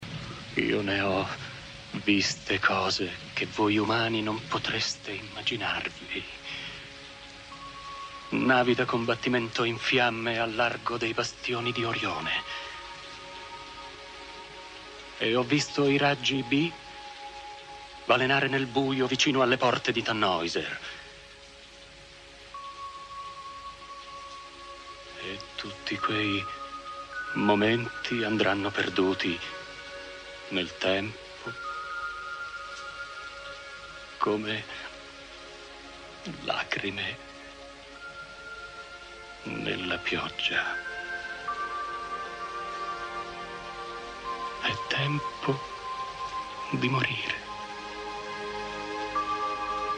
voce di Sandro Iovino dal film "Blade Runner", in cui doppia Rutger Hauer.